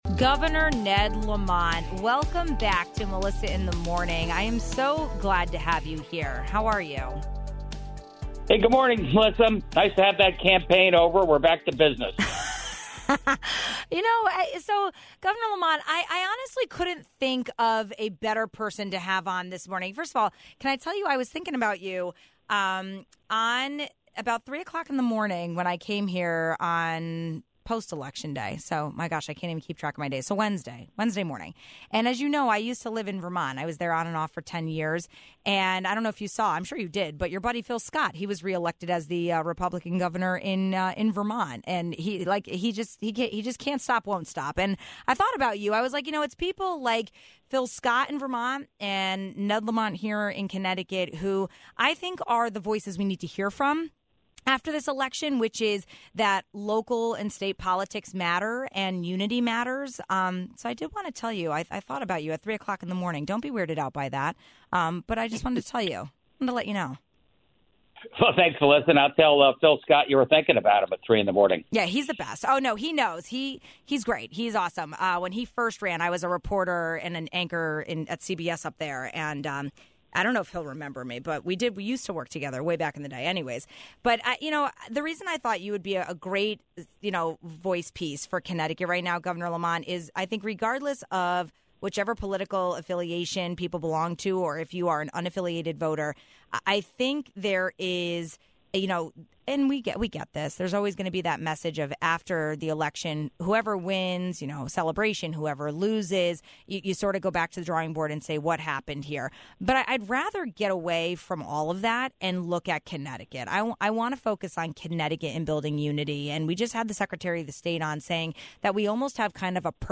Following the 2024 election, we wanted to talk to Connecticut’s governor about finding unity in our state politics despite controversy nationwide.